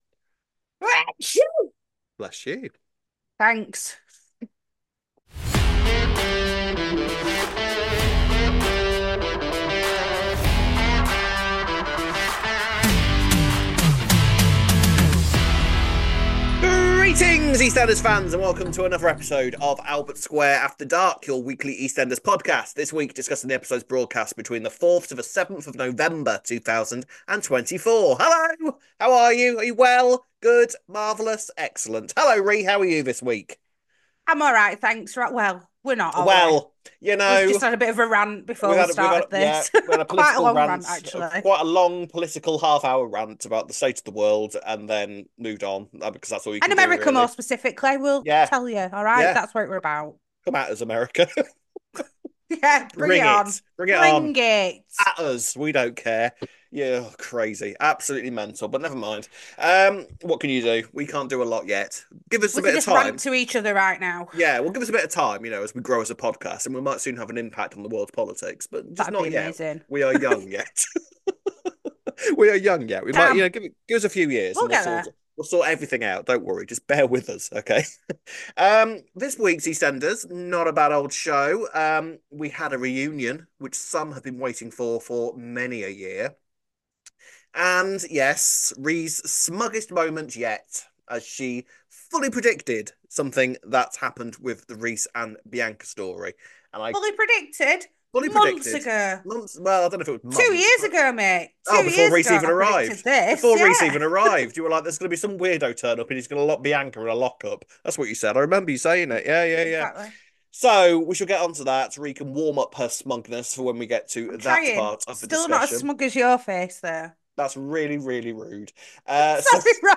sermon
Event: Sunday School